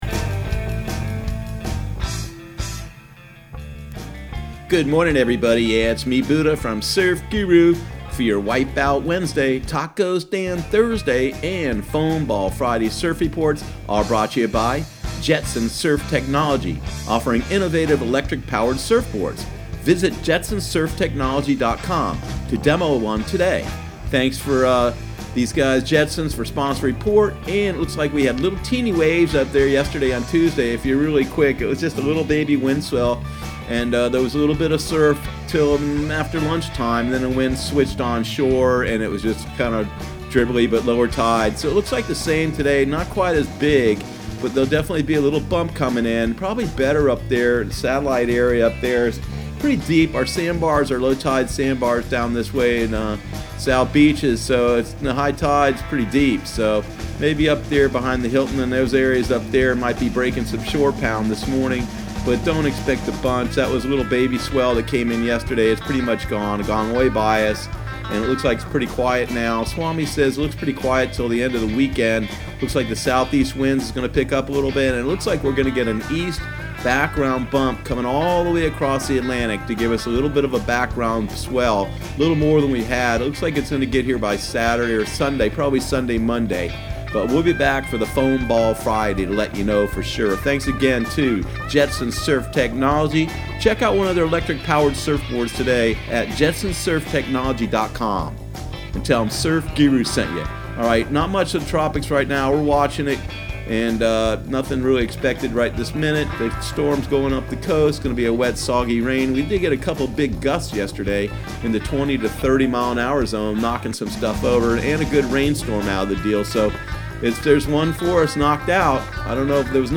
Surf Guru Surf Report and Forecast 07/24/2019 Audio surf report and surf forecast on July 24 for Central Florida and the Southeast.